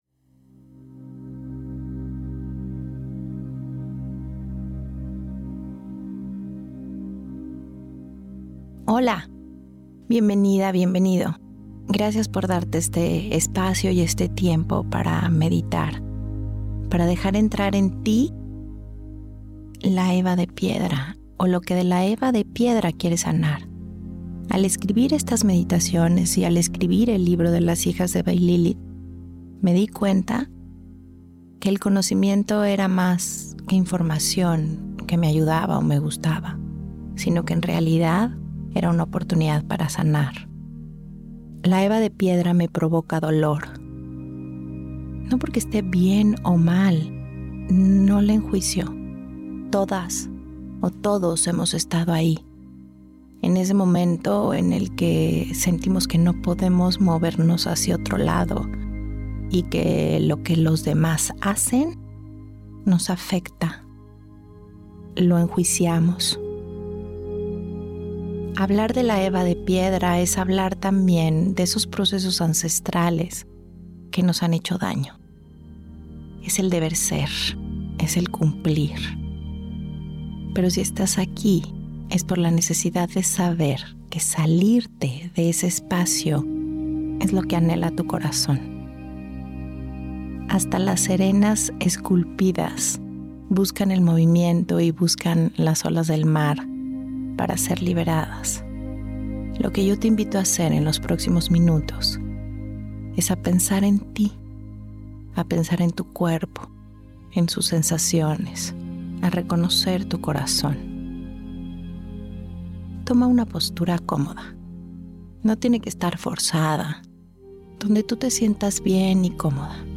Meditaciones Eva de Piedra Esta Eva te conecta con la voz interior que ha sido silenciada por el deber y las normas.